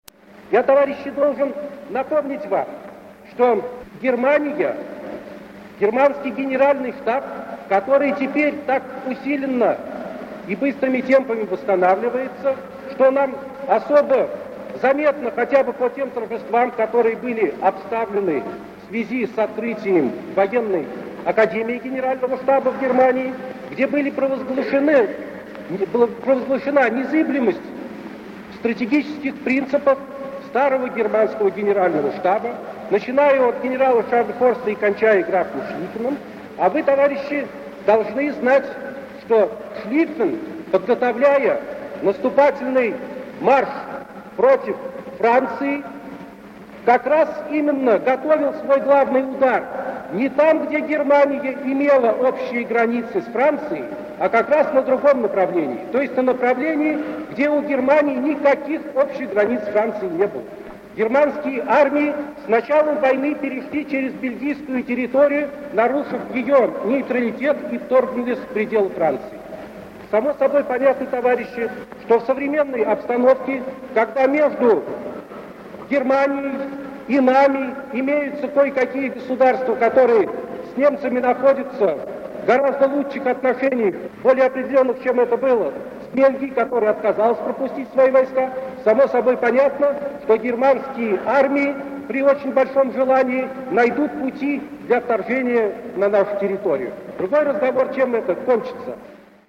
Тухачевский Михаил Николаевич - Выступление о возможности войны с фашистской Германией
Музыка / Аудиокниги, речи / Голоса истории / Альбом Циолковский Константин Эдуардович Konstantin Tsiolkovsky